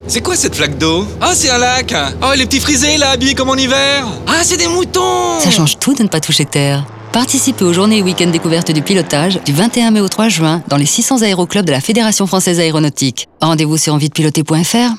spot publicitaire de la FFA (fichier Son - 2.7 Mo)